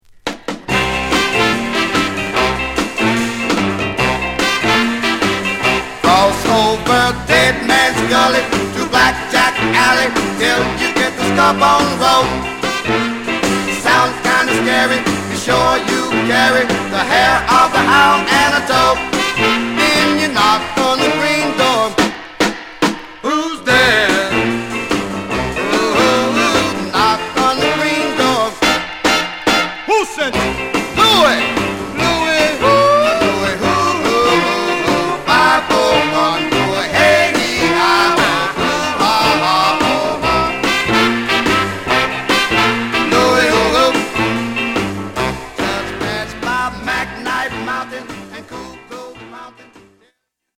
ファンキーなオケに陽気に唄うヴォーカルが印象的なA1